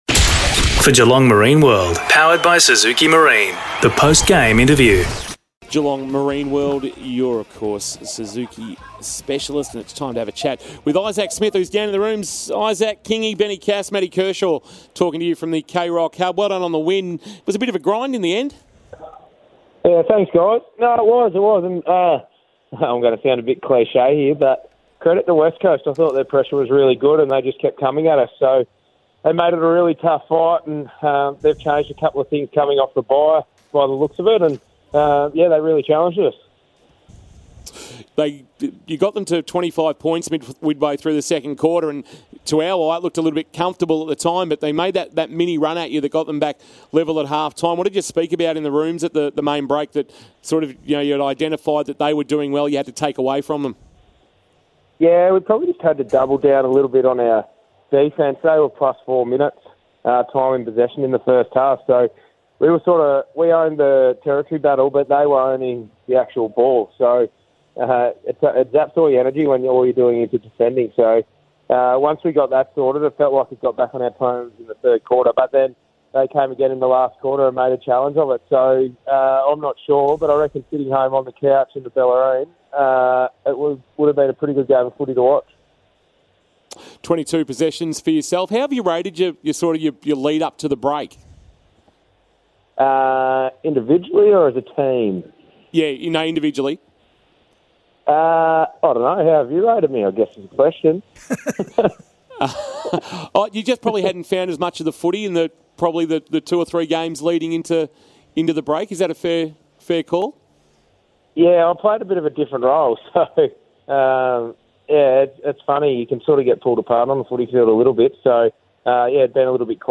2022 - AFL ROUND 14 - WEST COAST vs. GEELONG: Post-match interview - Isaac Smith (Geelong Cats)